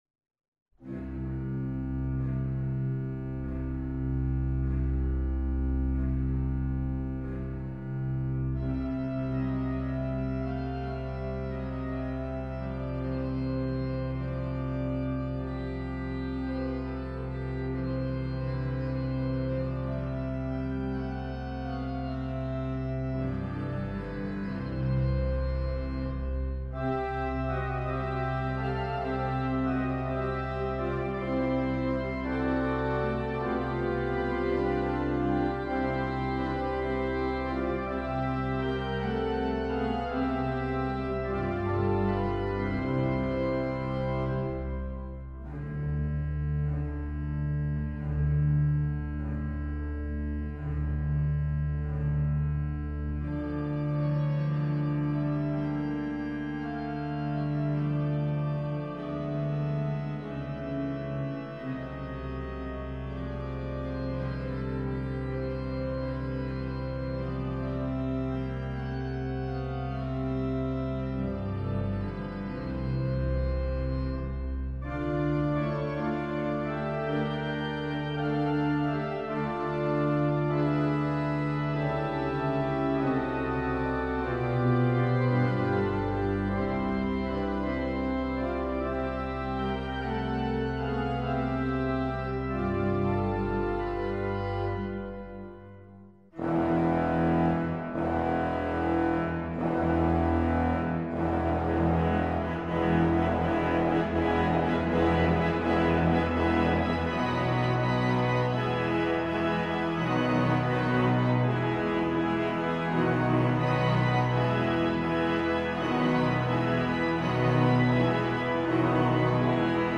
spielt auf der Stumm-Orgel der St. Johannes-Kirche im benachbarten Hambuch
Die Orgel stammt aus dem Jahr 1851 und verfügt über 22 Register verteilt auf zwei Manuale und Pedal.